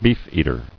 [beef·eat·er]